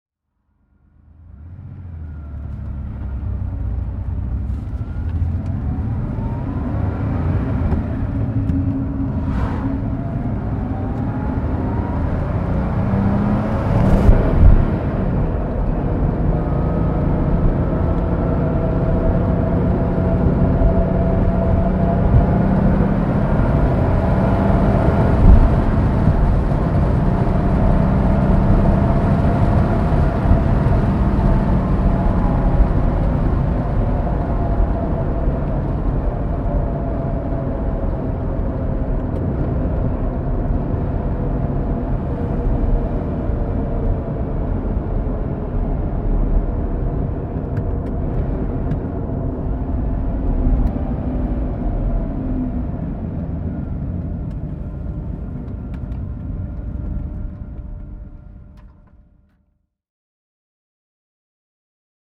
Motorsounds und Tonaufnahmen zu Porsche Fahrzeugen (zufällige Auswahl)
Porsche 930 Turbo (1976) - Innengeräusch in Fahrt
Porsche_Turbo_1976_-_Innengeraeusch.mp3